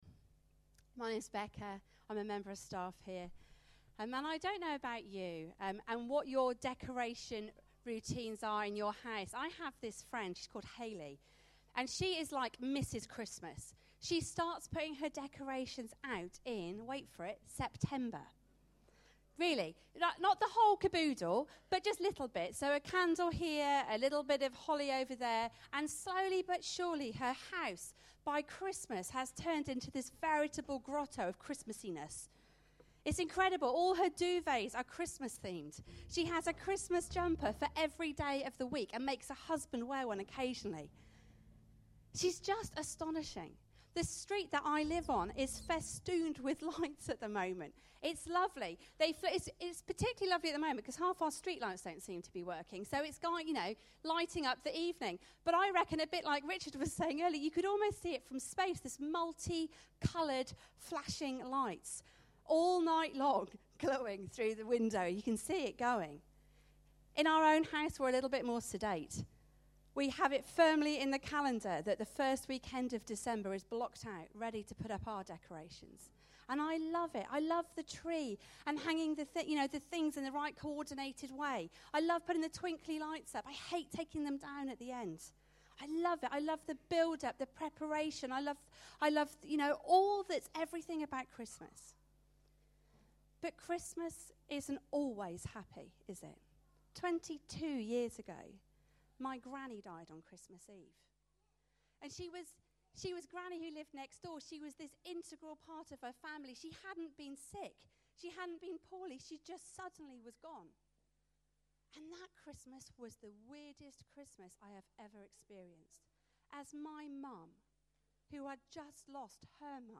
Carol Service 2016